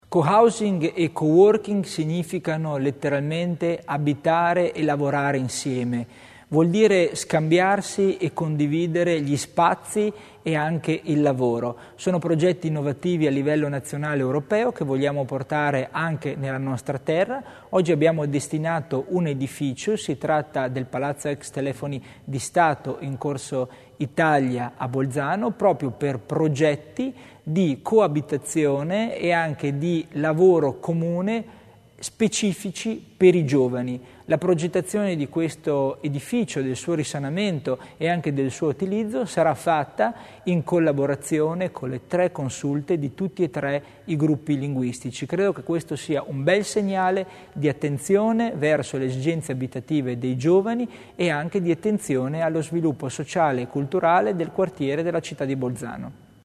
L'Assessore Tommasini spiega il progetto di cohousing e coworking